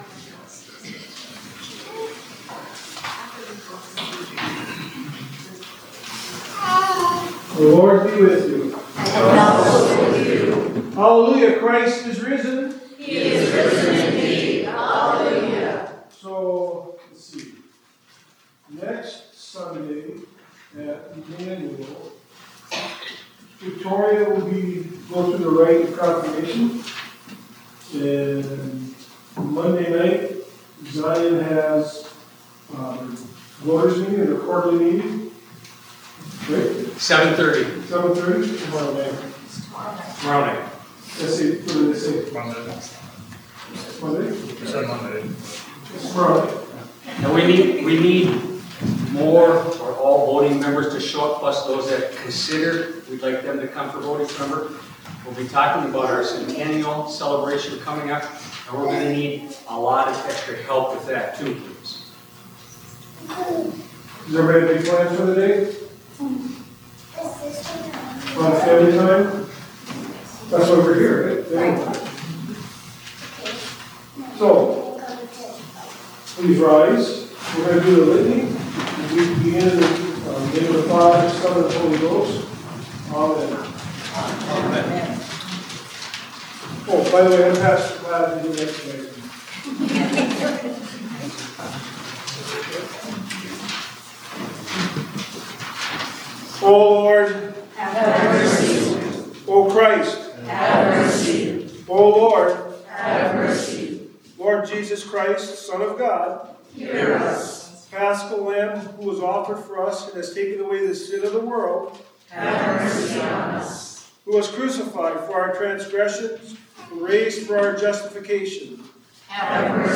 Zion Worship 20 Apr 25 Easter